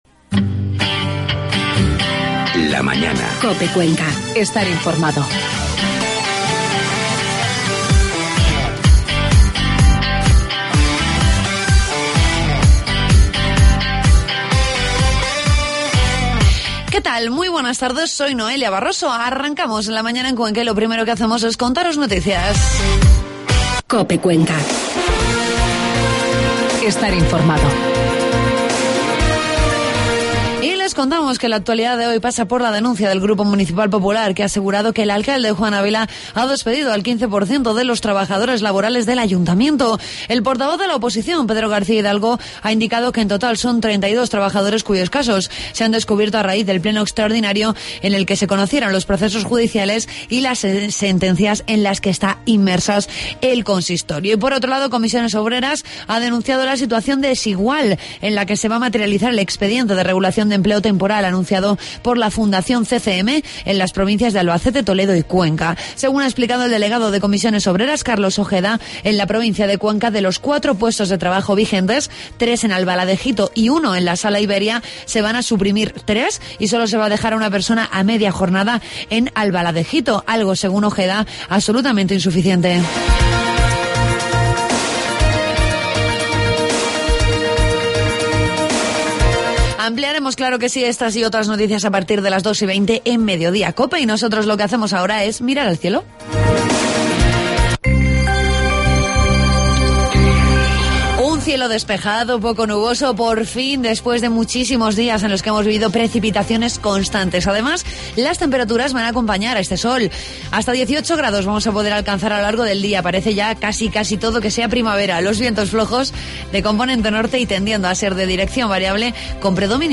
Entrevistamos al portavoz del Grupo Municipal Popular, Pedro García Hidalgo....